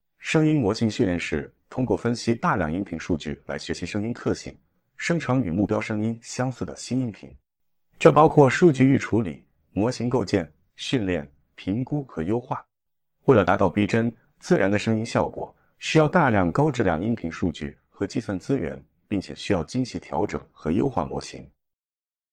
模型参数：扩散+聚变
声音输出试听